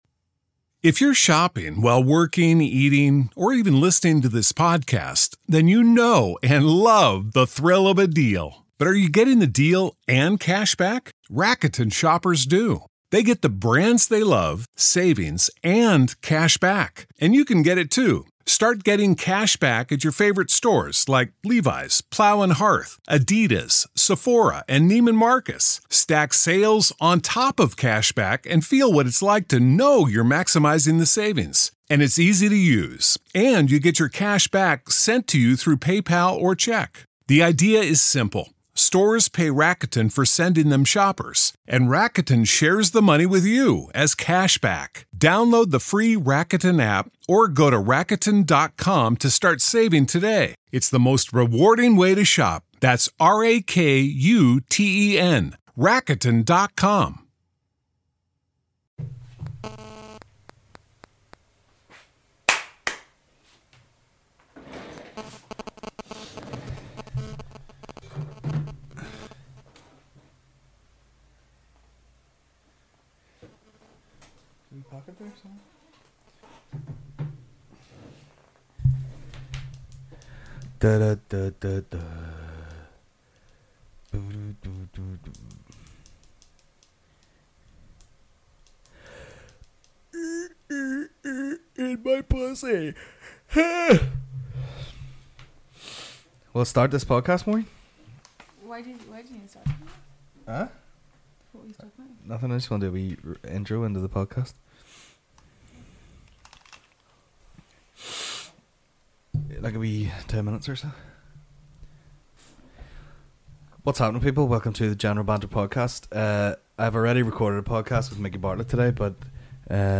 General Banter Podcast Comedy